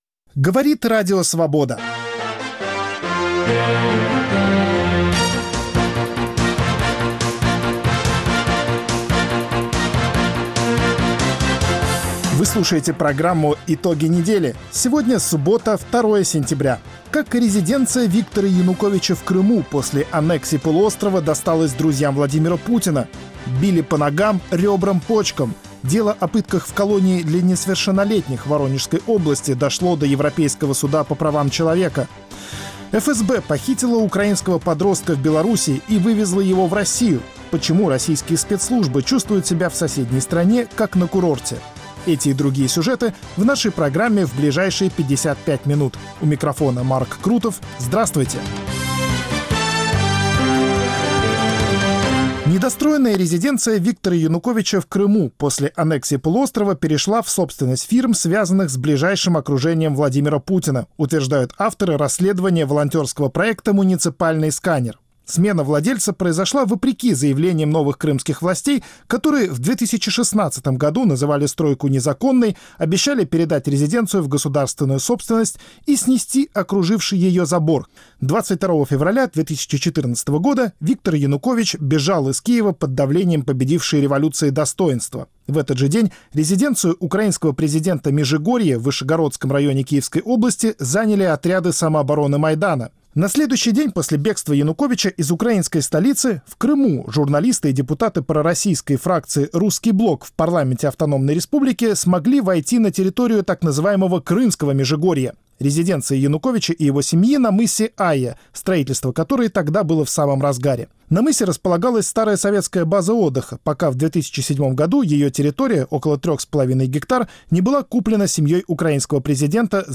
Политические, экономические, социальные, культурные, светские итоги недели в России и в мире подводит в прямом эфире